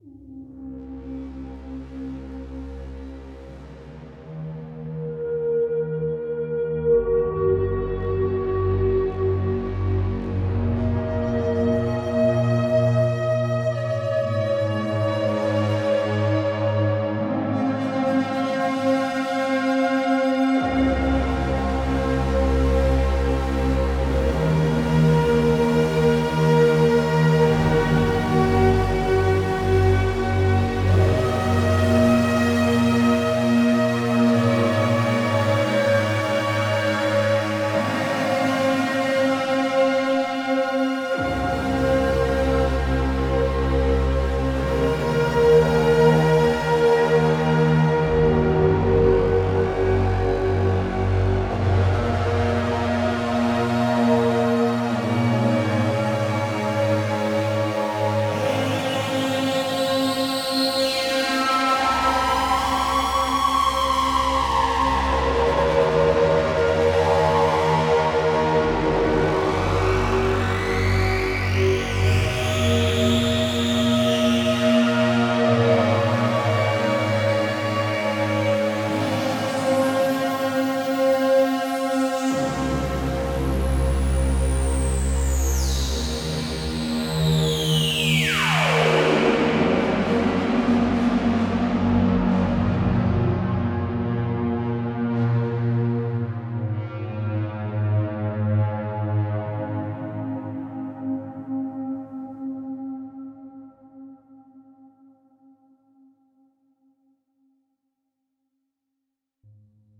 I’m pretty amazed at just how gnarly the Typhon can get. First run with it with one of the on-board reverbs with a bit of added Valhalla Vintage Verb.
It’s merely two takes added together.